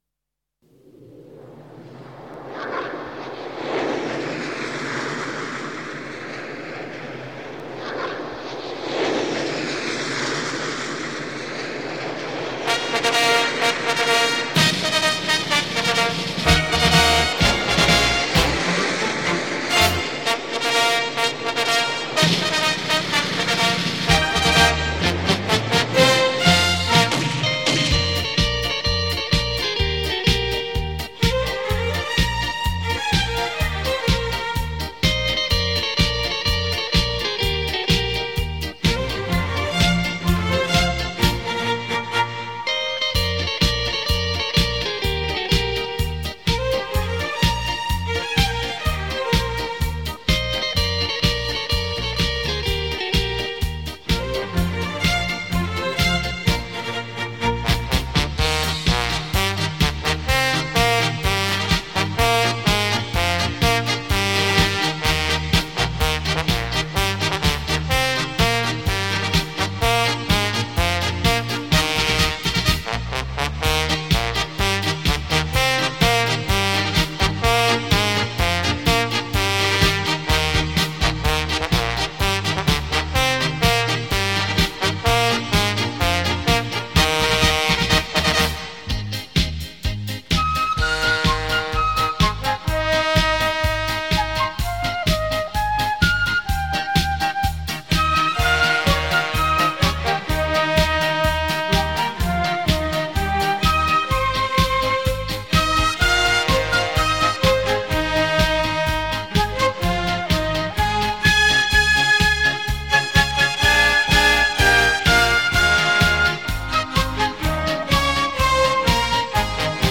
超时空立体音效 百万名琴魅力大出击
电声发烧珍品·值得您精心收藏·细细聆赏...